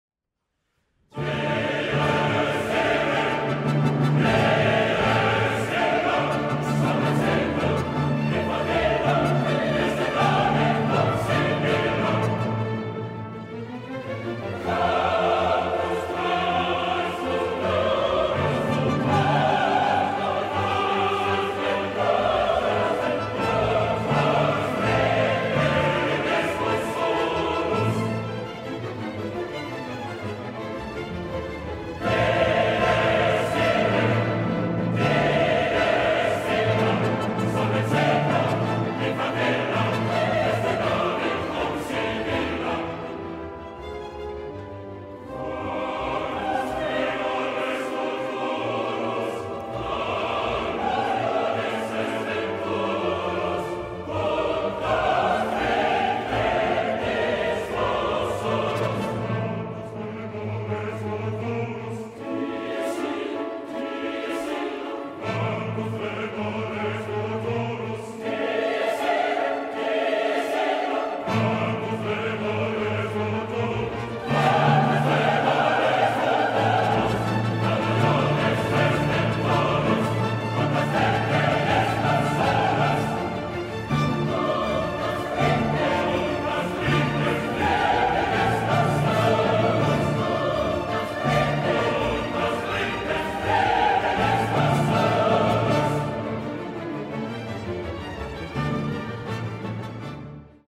El Réquiem en re menor, K. 626 es una misa basada en los textos latinos para el acto litúrgico católico en torno del fallecimiento de una persona.